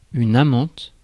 Ääntäminen
US : IPA : [ˈɡɝl.ˌfɹɛnd]